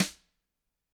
Index of /90_sSampleCDs/ILIO - Double Platinum Drums 1/CD4/Partition C/GRETCHBRSNRD